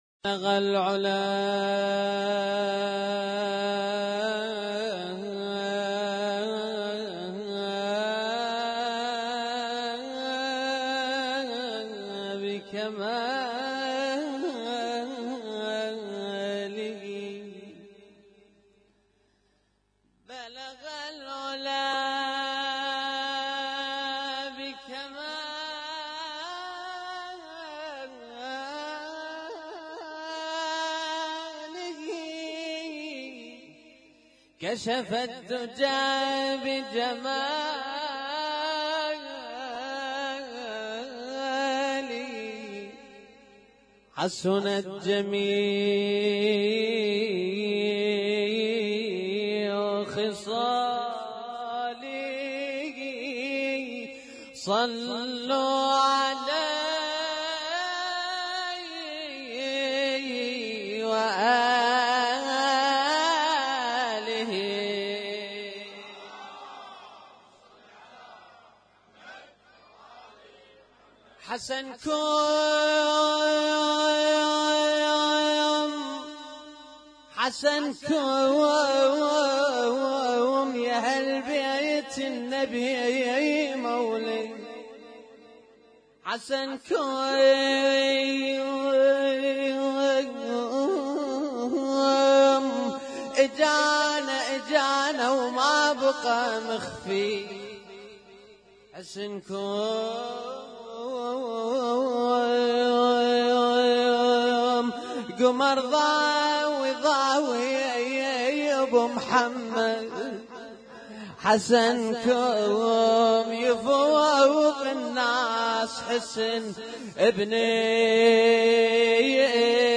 Husainyt Alnoor Rumaithiya Kuwait
اسم النشيد:: ليلة 15 من شهر رمضان 1438 - مولد الإمام الحسن المجتبى عليه السلام
القارئ: الرادود
اسم التصنيف: المـكتبة الصــوتيه >> المواليد >> المواليد 1438